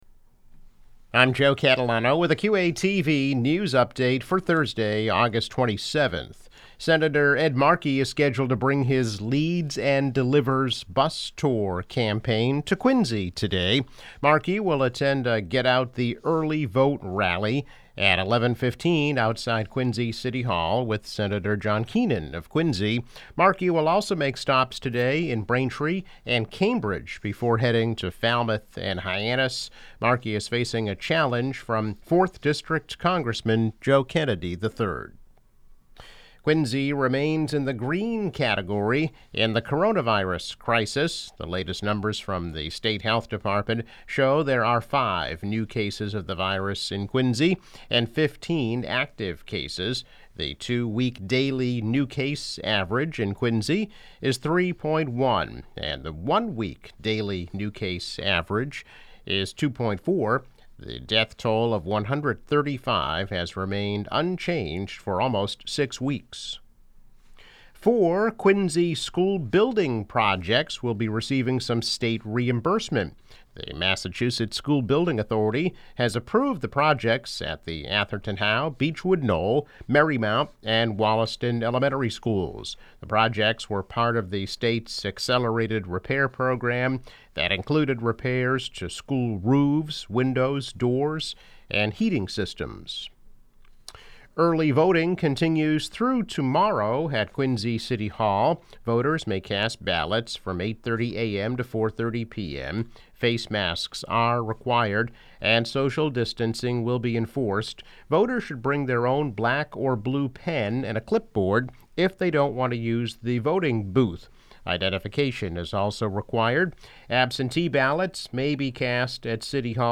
News Update - August 27, 2020